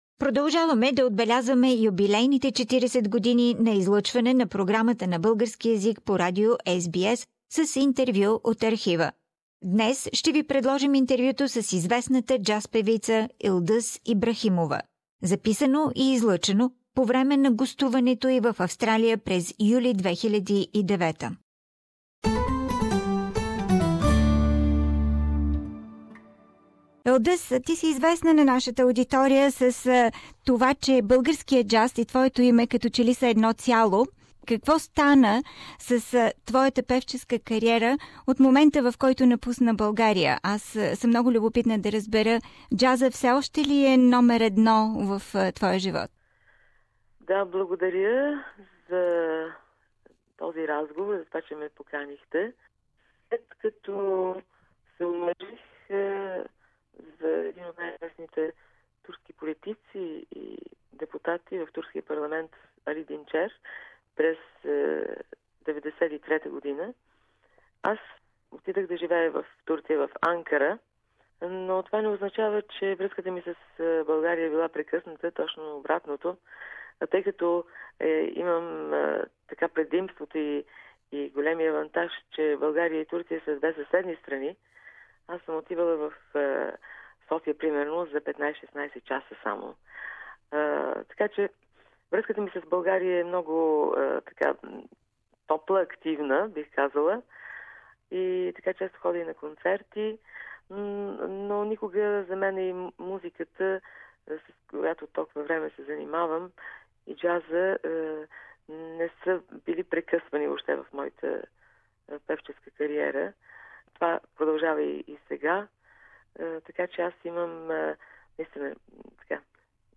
Interview with jazz singer Yıldız İbrahimova